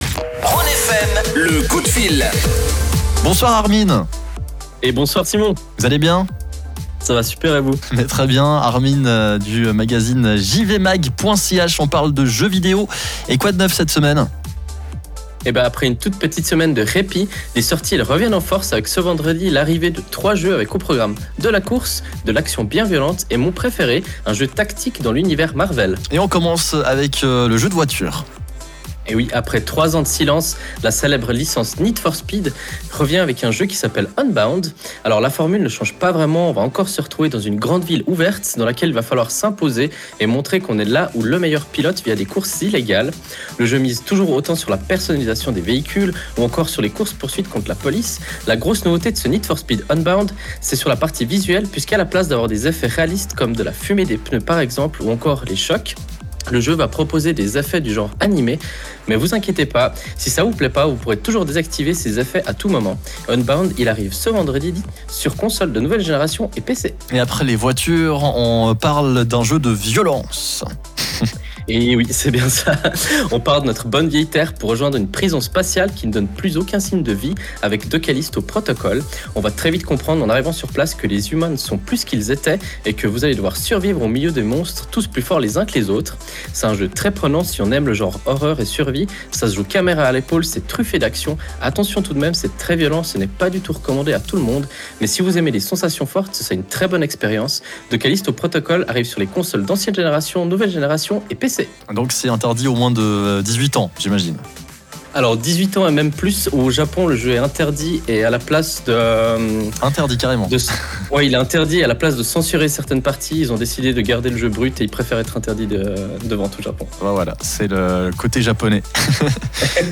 Le direct est à réécouter juste en dessus.